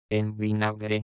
Primo tipo
Si pronunciano chiudendo le labbra.